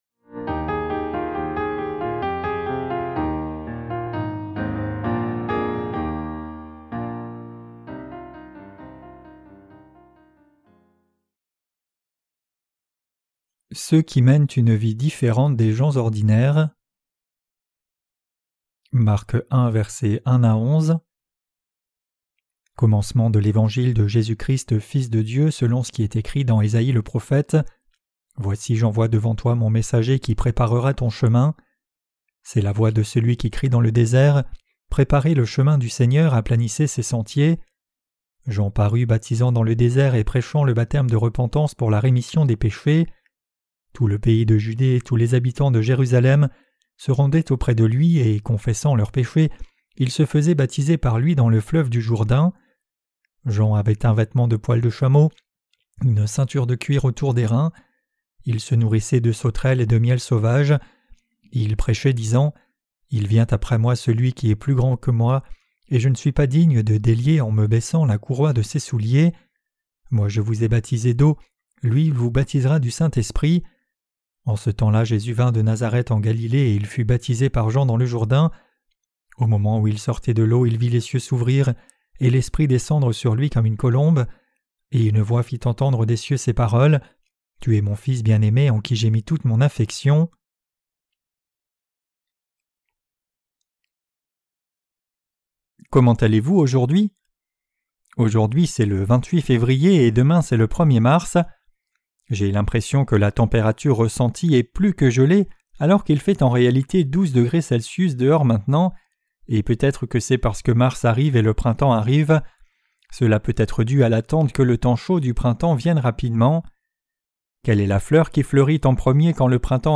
Sermons sur l’Evangile de Marc (Ⅰ) - QUE DEVRIONS-NOUS NOUS EFFORCER DE CROIRE ET PRÊCHER? 2.